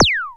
Click-01.wav